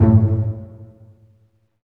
Index of /90_sSampleCDs/Roland LCDP13 String Sections/STR_Vcs Marc&Piz/STR_Vcs Pz.2 amb
STR PIZZ.M06.wav